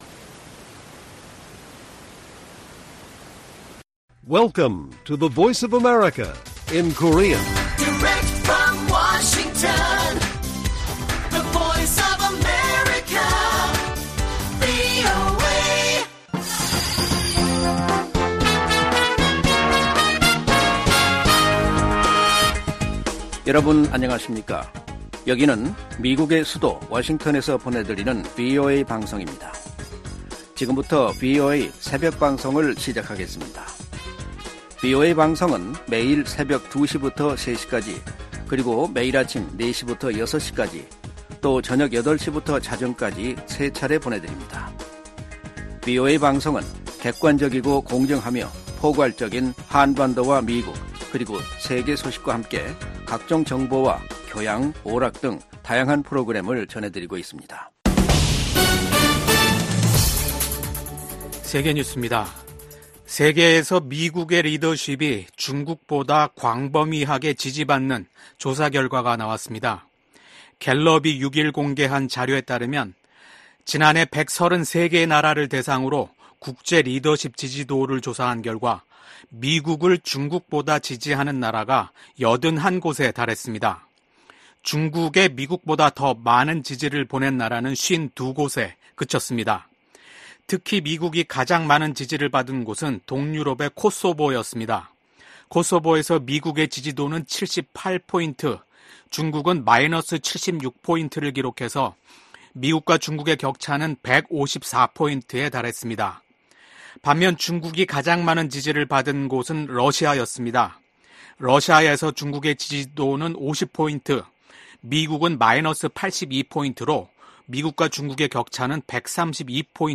VOA 한국어 '출발 뉴스 쇼', 2024년 5월 7일 방송입니다. 미국과 일본, 호주 국방장관들이 북러 군사협력 심화와 북한의 반복적인 미사일 발사를 강력히 규탄했습니다. 북한이 아무런 댓가없이 러시아에 무기를 지원하는 것은 아니라고 미국 관리가 말했습니다.